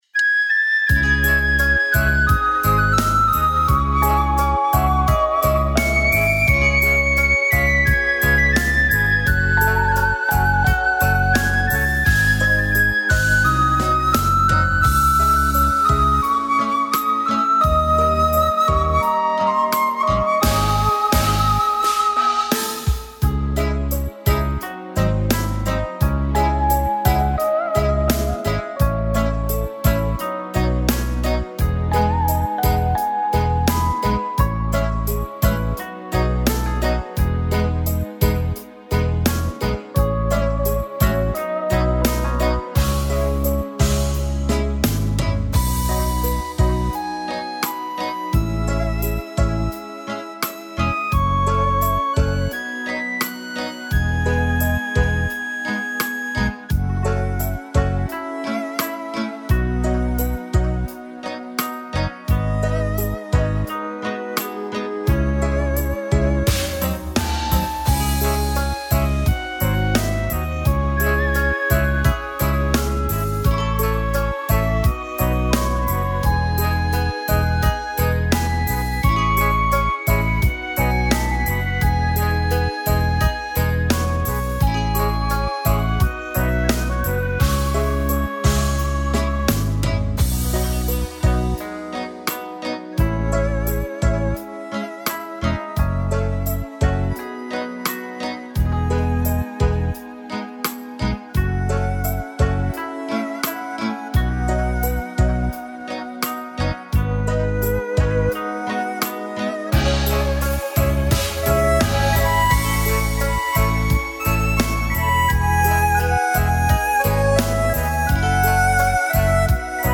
Tone Nữ (C#m)
Karaoke Melody